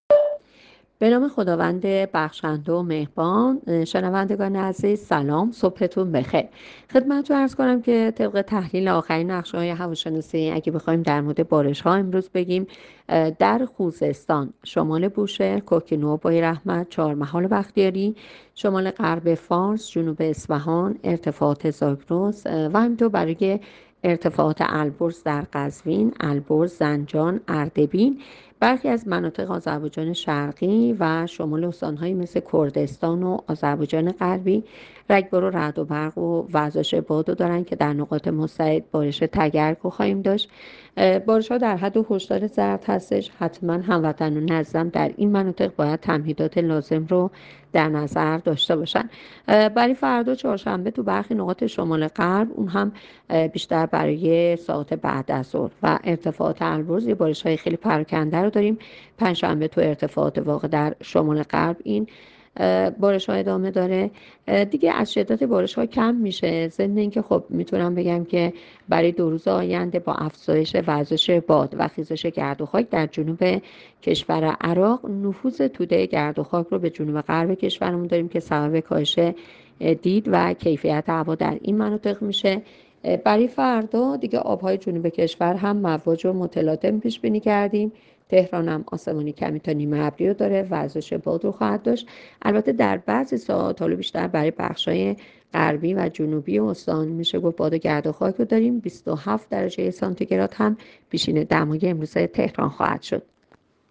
گزارش رادیو اینترنتی پایگاه‌ خبری از آخرین وضعیت آب‌وهوای ۲ اردیبهشت؛